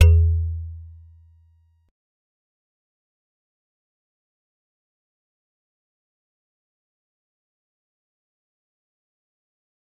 G_Musicbox-E2-pp.wav